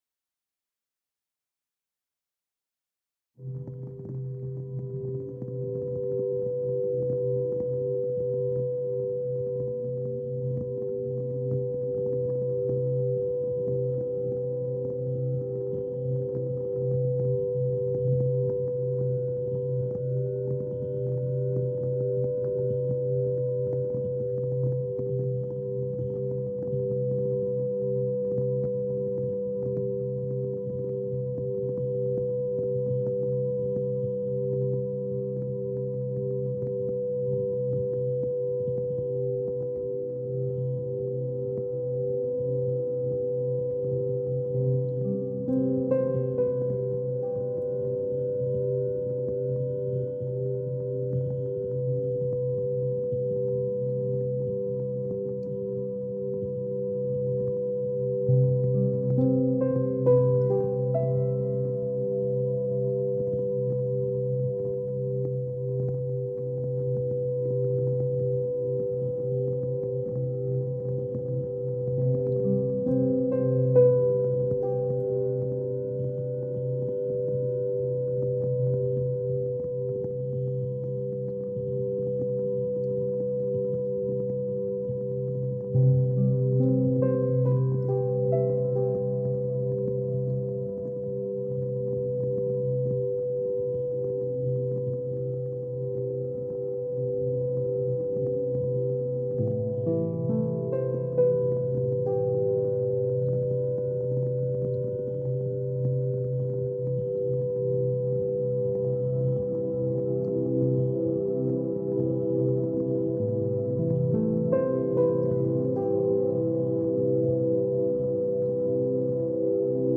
The sound of The Bridge was important for me to include in the piece as I feel partly responsible for it being considered a WHS.
In the piece, the train crossing the bridge represents not just a literal sound but an auditory symbol of the location itself.